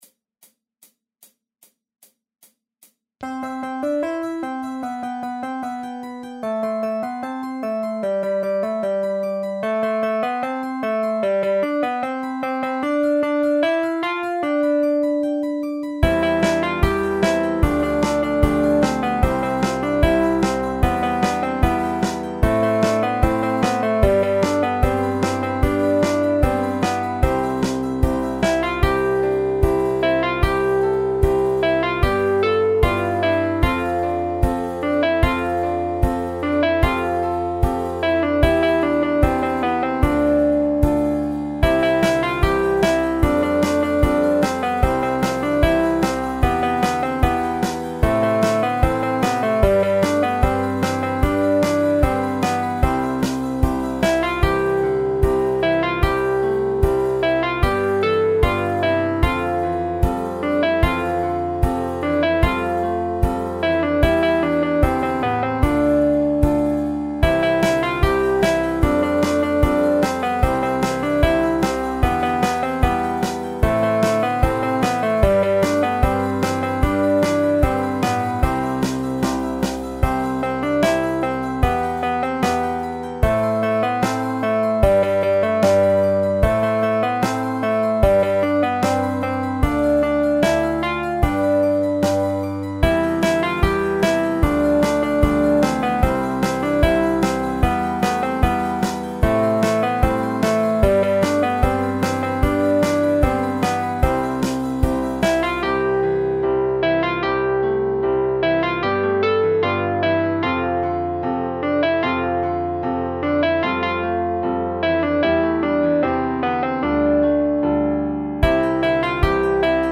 インストゥルメンタルエレクトロニカロング明るい